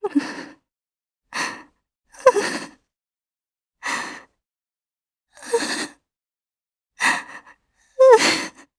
Isolet-Vox_Sad_jp.wav